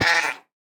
Minecraft Version Minecraft Version snapshot Latest Release | Latest Snapshot snapshot / assets / minecraft / sounds / mob / goat / hurt1.ogg Compare With Compare With Latest Release | Latest Snapshot
hurt1.ogg